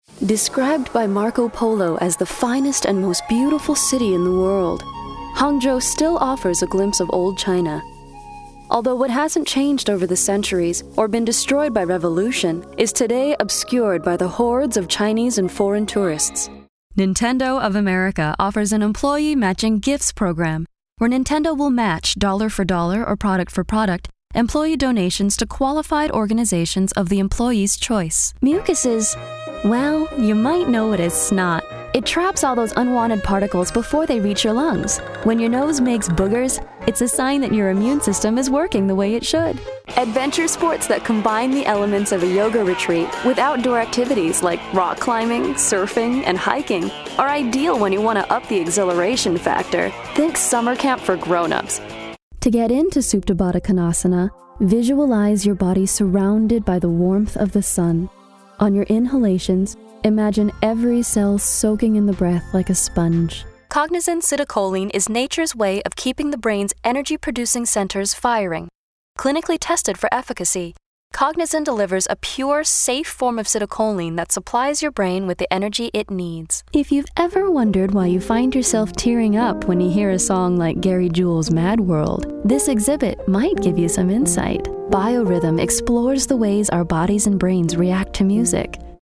NARRATION, CORPORATE
Narration Demo: